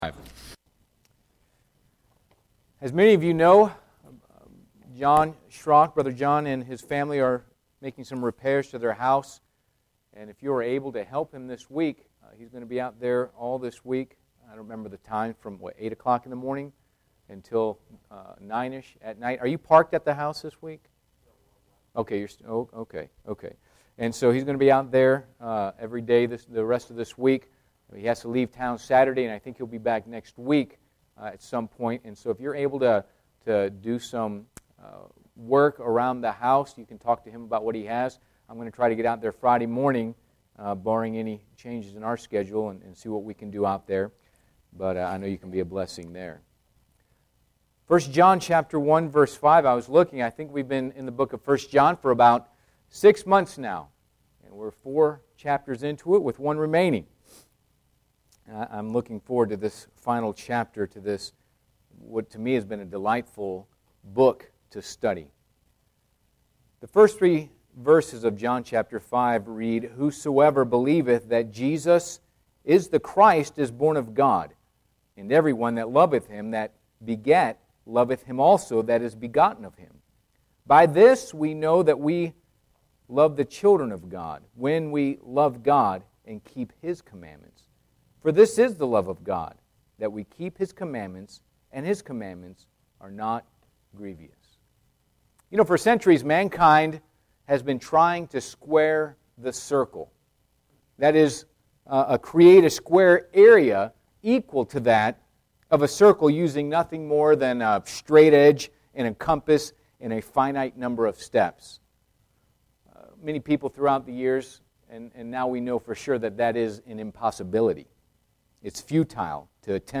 Passage: 1 John 5:1-3 Service Type: Midweek Service Bible Text